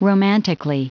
Prononciation du mot romantically en anglais (fichier audio)
Prononciation du mot : romantically